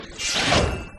Телекинез звук из Зачарованных